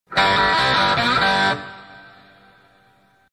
Song: Bad_to_the_Bone_opening_Riff.mp3